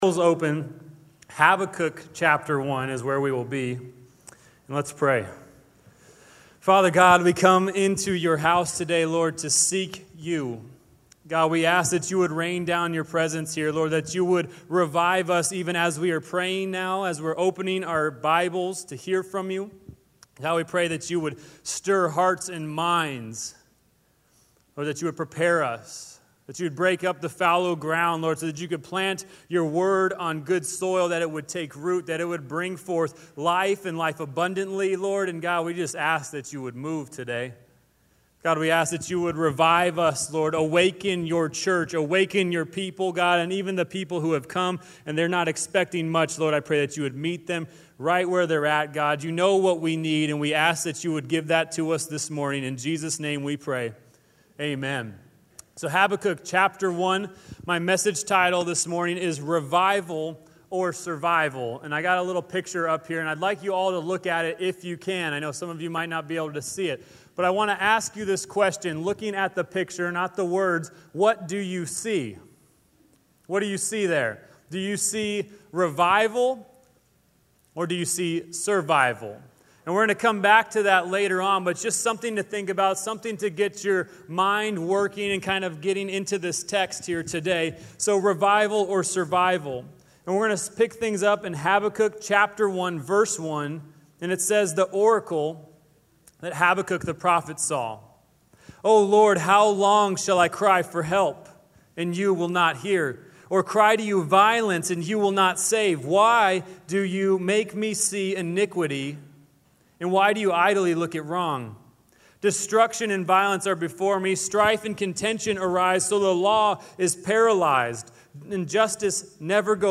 Guest speakers